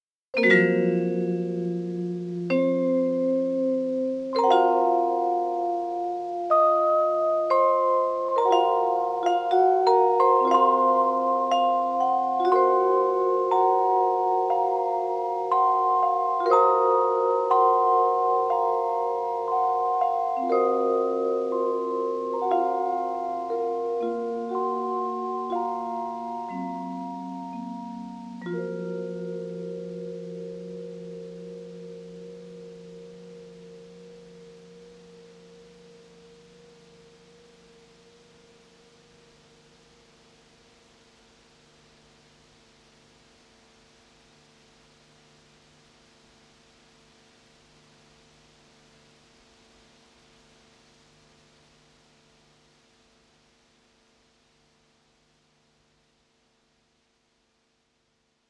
ThePhonoLoop Cassette Vibraphone使用4种不同的卡带录制了全音域（3个八度）的4个动态层。
它具有有机的声音，带有许多小的不完美之处。